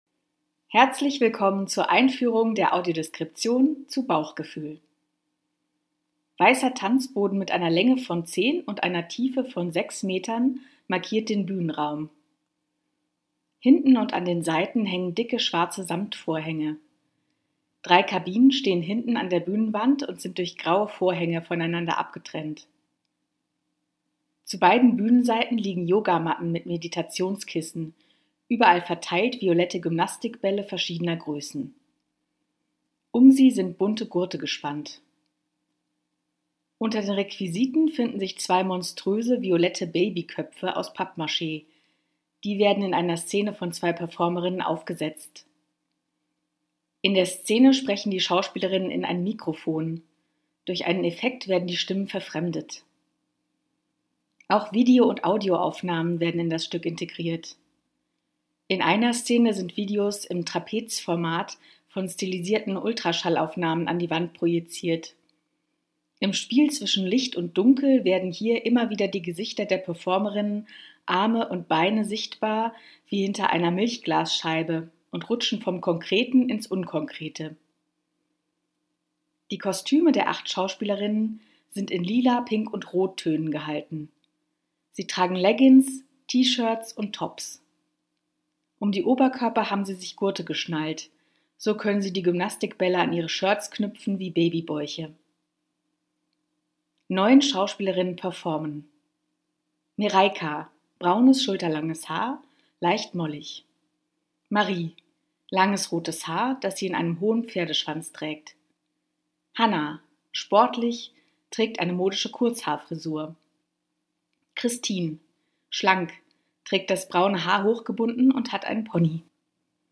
There is a audio description for the performance. You can listen to the introduction to the audio description here:
Audio Description Voice: